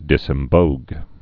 (dĭsĕm-bōg)